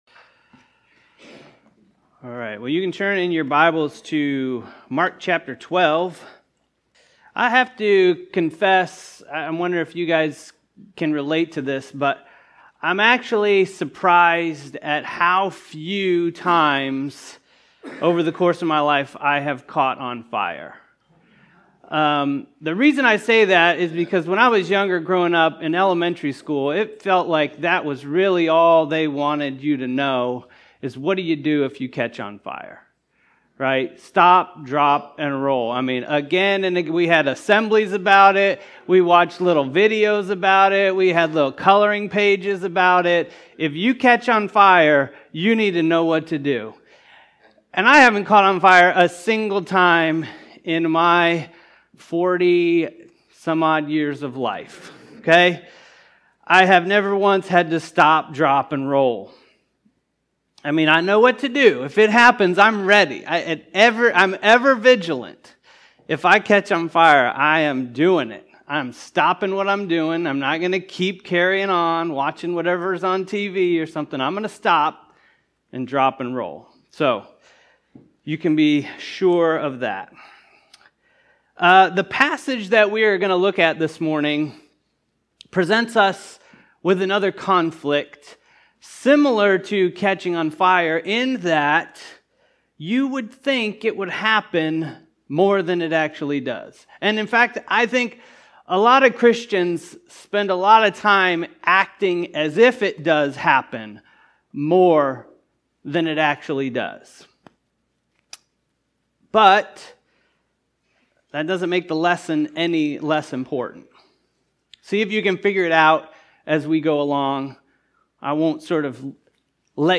In Mark 12:13-17, Jesus silences His critics with a profound answer on taxes and allegiance. This sermon unpacks His wisdom, showing how to live faithfully under human government while honoring God above all.